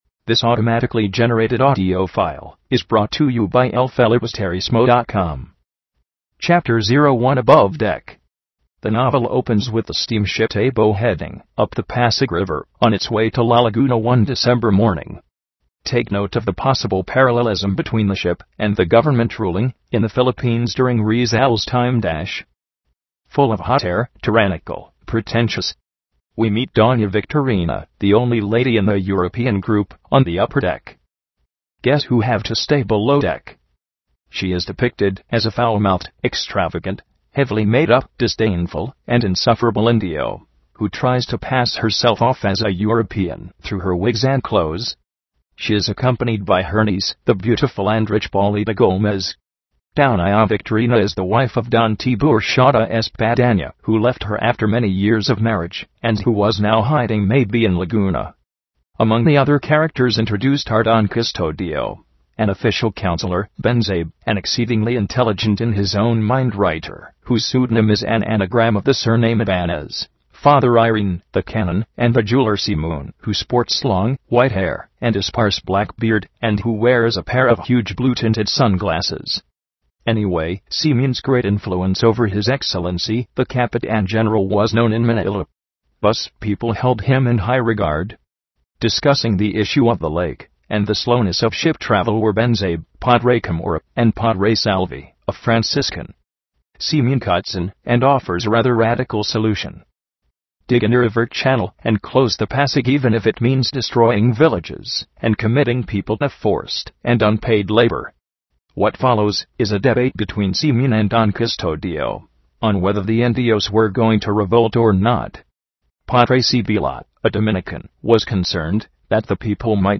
Here are the mp3 files (text files read by a robot), just in case you would like to download these into your mp3 players and listen to the notes while you're away from your computer (i.e., while commuting, just before you drift off to sleep):